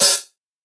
Verby Hi Hat One Shot E Key 68.wav
Royality free hat sample tuned to the E note. Loudest frequency: 7985Hz
verby-hi-hat-one-shot-e-key-68-mmZ.mp3